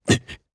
Lusikiel-Vox_Damage_jp_01.wav